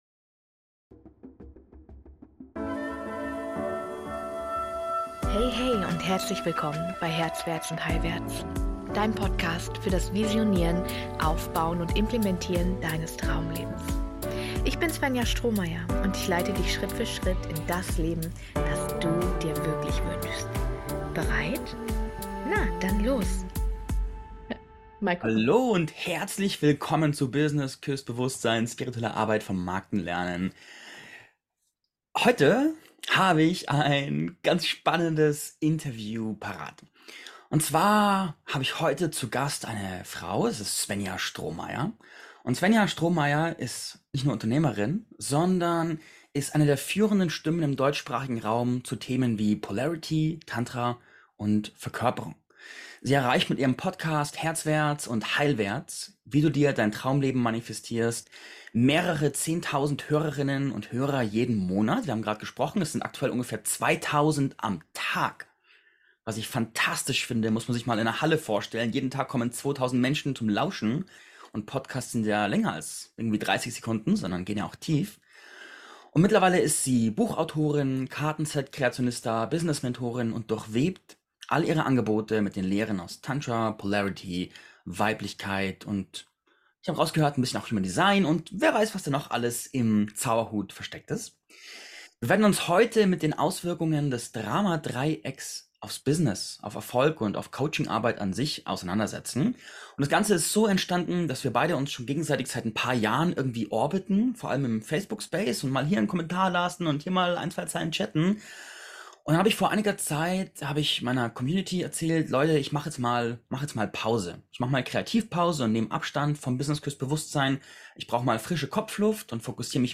Das Drama-Dreieck im Business – Interview